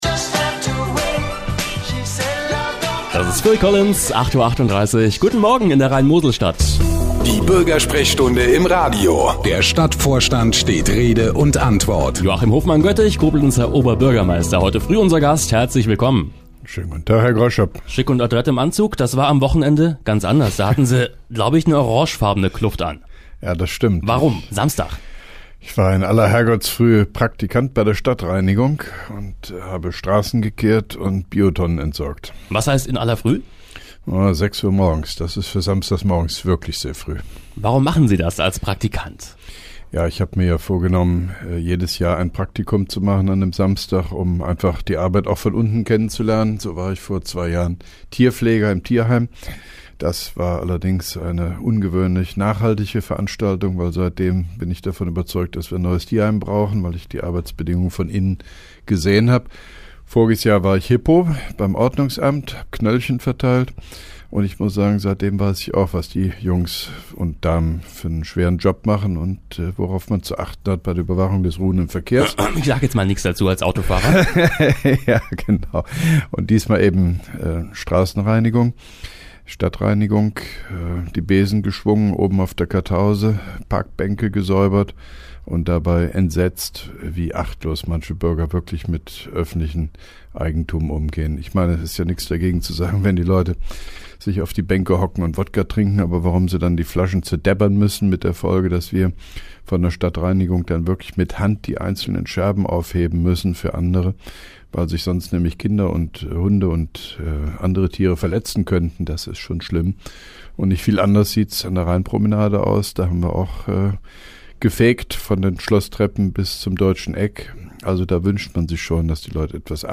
(2) Koblenzer Radio-Bürgersprechstunde mit OB Hofmann-Göttig 08.05.2012
Antenne Koblenz 98,0 am 08.05.2012, ca. 8.38 Uhr, (Dauer 04:30 Minuten)